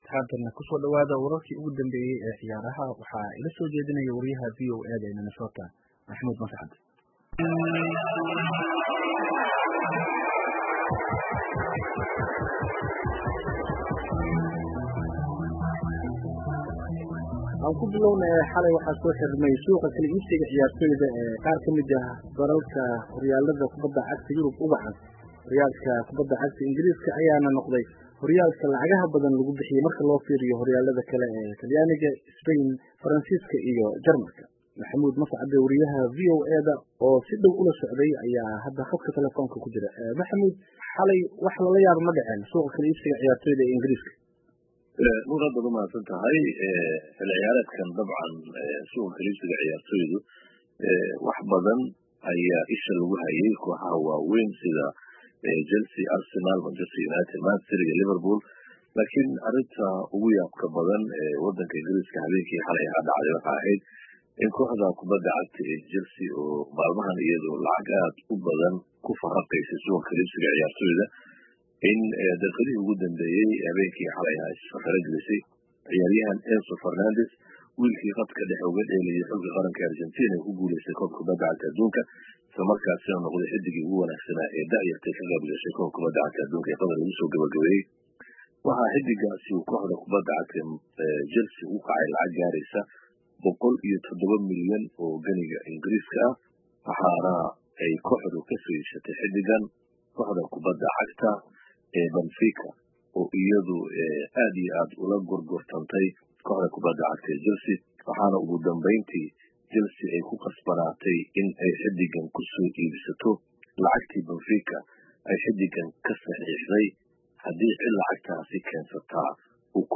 Falanqeynta Suuqa kala iibsiga.mp3